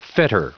Prononciation du mot fetter en anglais (fichier audio)
Prononciation du mot : fetter